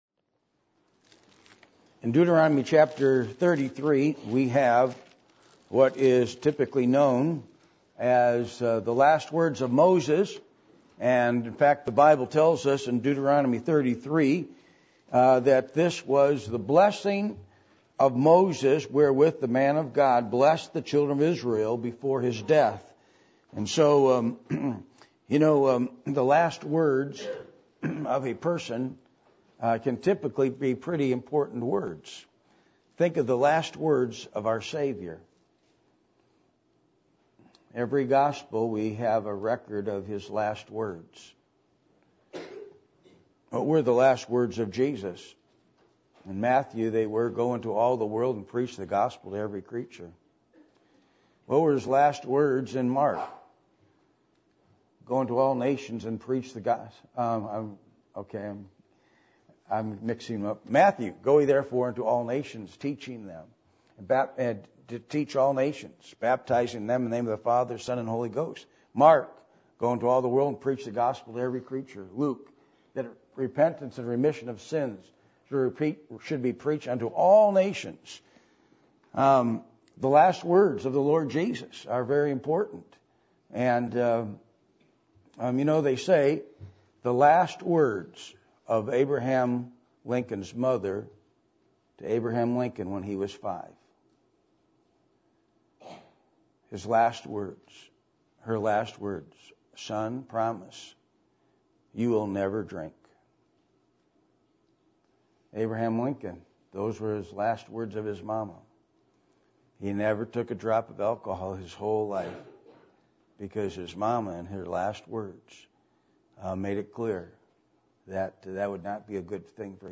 Passage: Deuteronomy 33:1-29 Service Type: Sunday Morning %todo_render% « Do You Love The Lord Jesus?